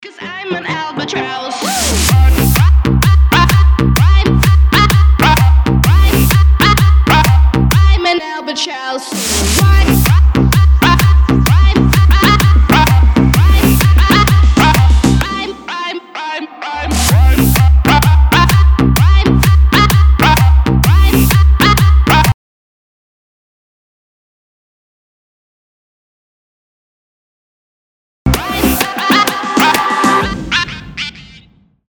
зажигательные
веселые
electro house
bounce
Melbourne Bounce
электро-свинг
Весёлая музыка, чтобы отвлечься